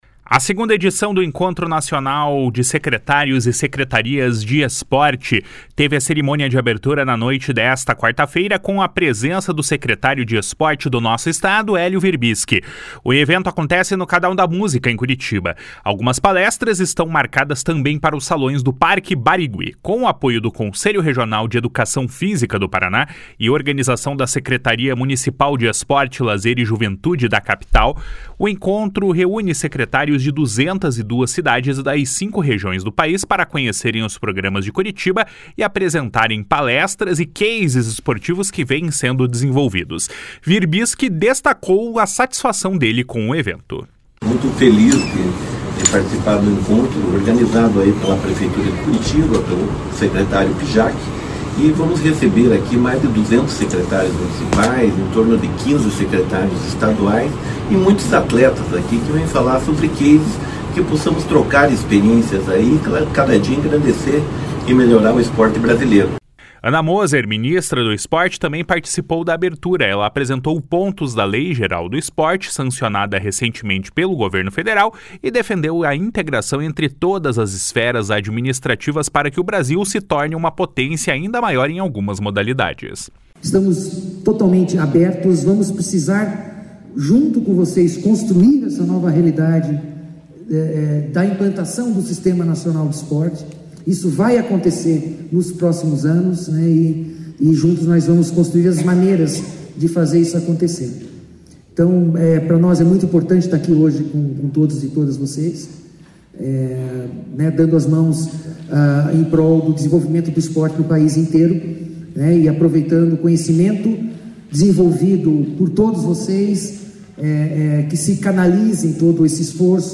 // SONORA HELIO WIRBISKI //
// SONORA ANA MOSER //